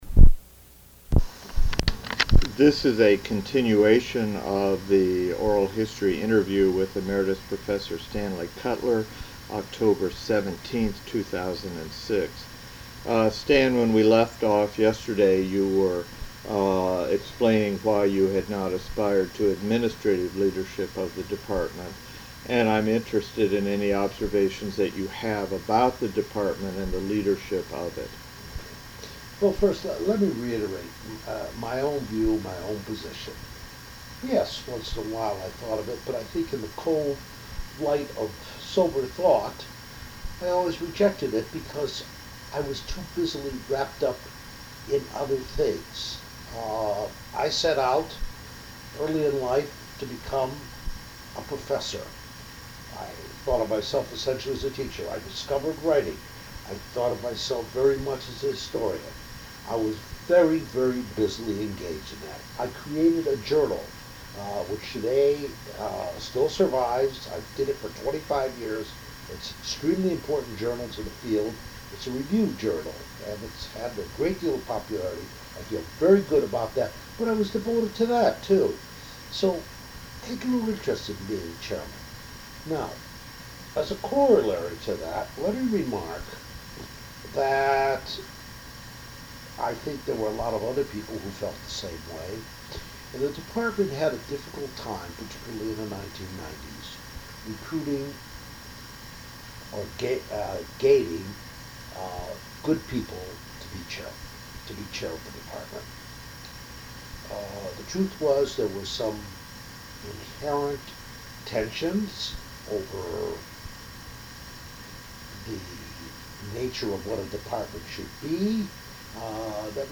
Oral History Interview: Stanley Kutler (0817)